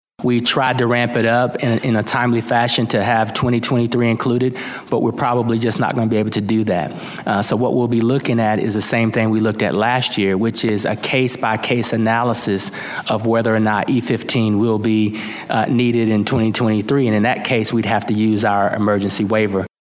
Environmental Protection Agency chief Michael Regan was called out on biofuel use, pesticide registrations, and the Biden Waters of the U.S. rule at a House Ag hearing.